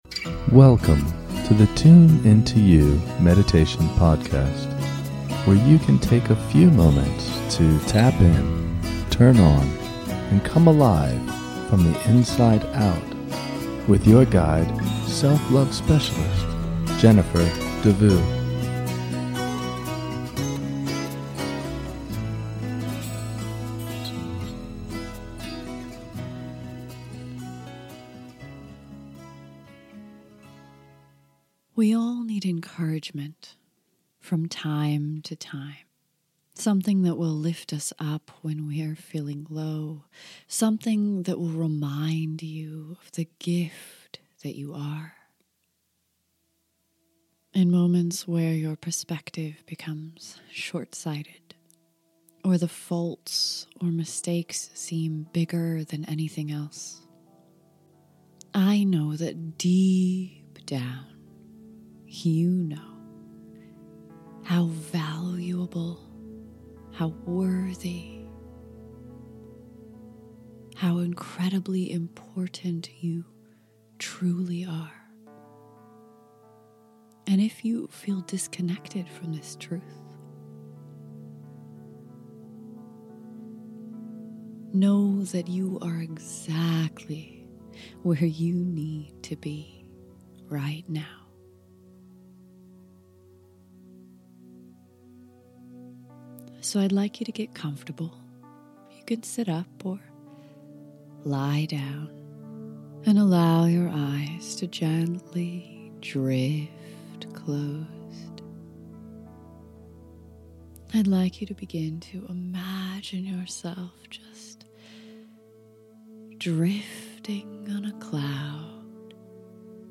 This short guided meditation will soothe, comfort, and remind you of the truth of you.